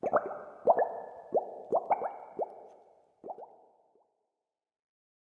Minecraft Version Minecraft Version 25w18a Latest Release | Latest Snapshot 25w18a / assets / minecraft / sounds / block / bubble_column / upwards_ambient5.ogg Compare With Compare With Latest Release | Latest Snapshot
upwards_ambient5.ogg